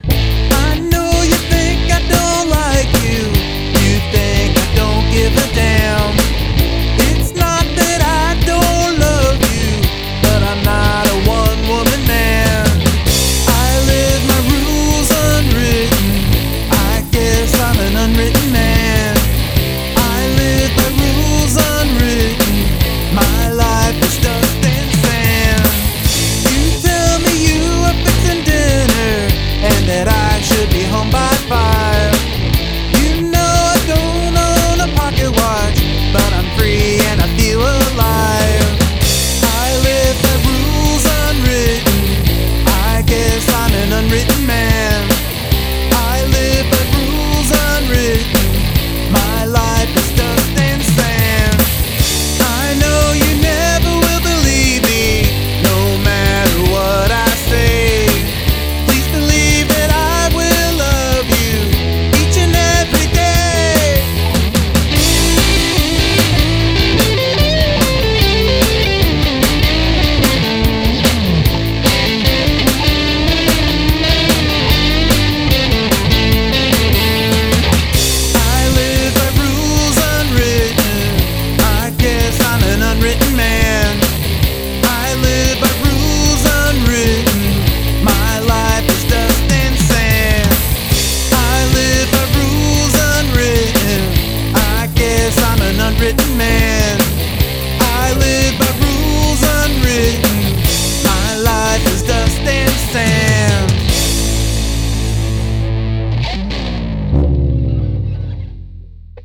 Spiky and full of energy.